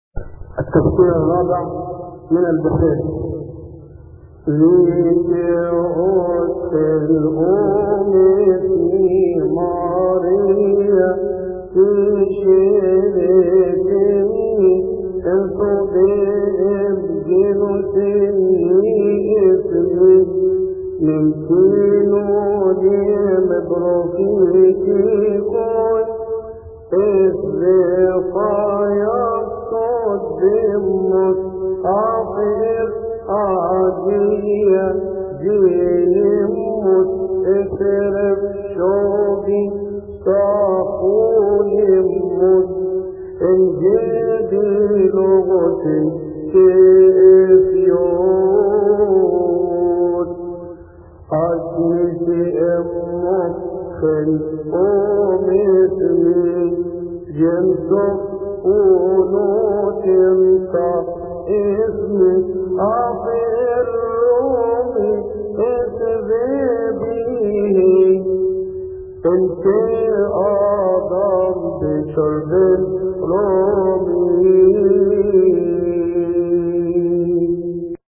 يصلي في تسبحة عشية أحاد شهر كيهك